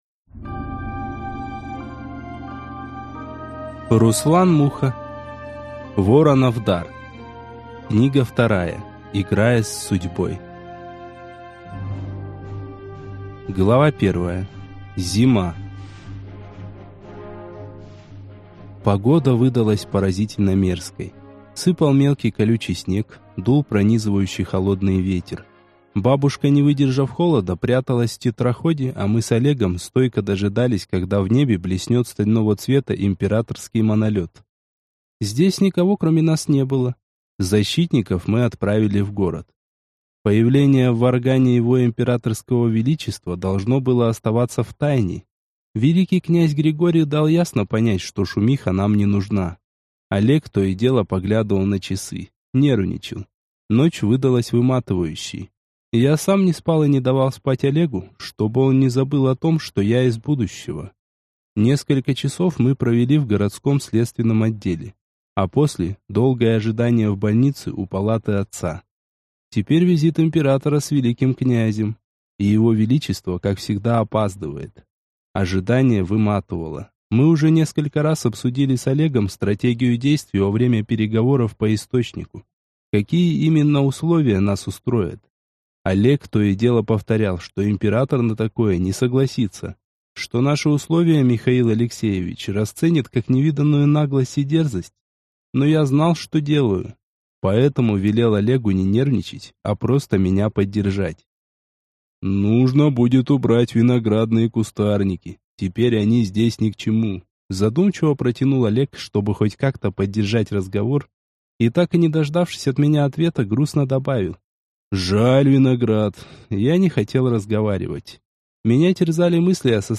Аудиокнига Играя с Судьбой | Библиотека аудиокниг